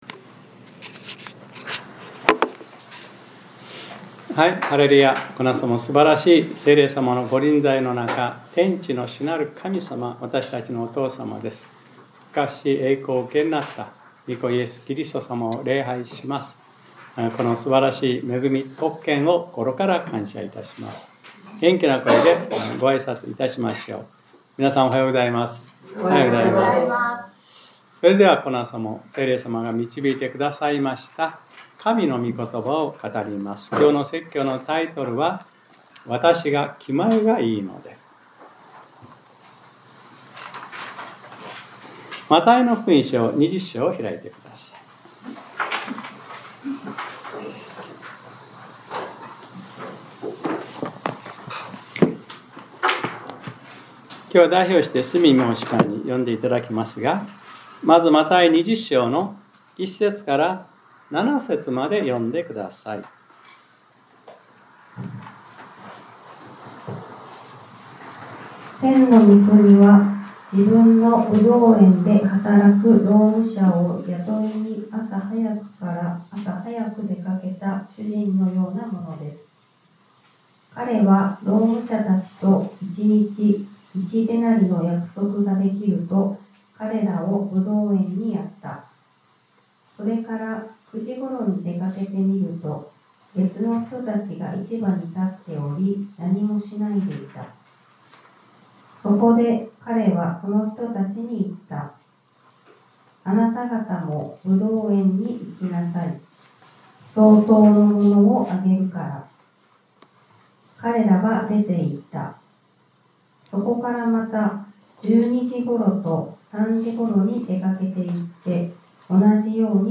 2026年03月08日（日）礼拝説教『 わたしが気前がいいので 』 | クライストチャーチ久留米教会